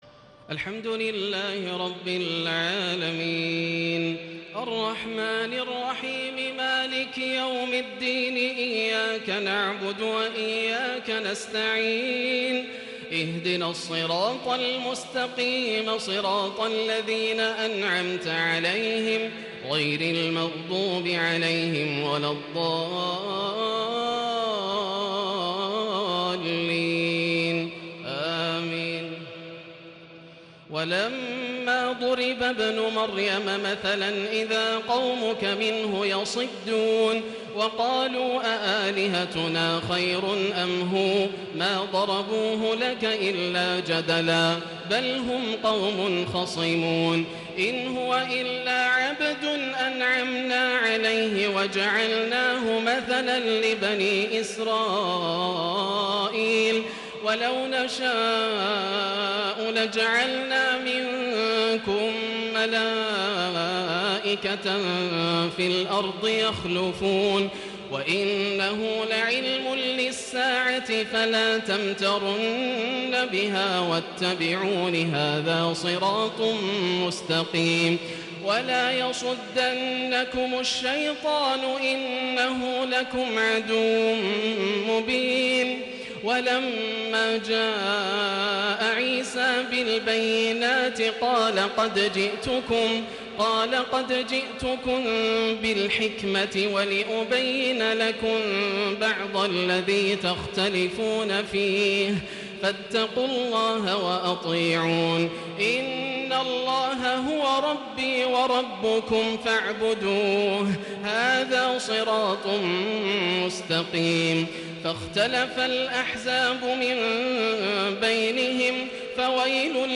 ( ونادوا يامالك ) > الروائع > رمضان 1437هـ > التراويح - تلاوات ياسر الدوسري